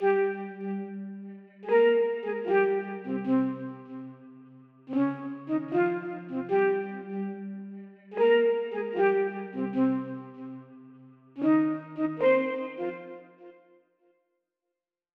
(Flute) NightCrawler_148Bpm(1).wav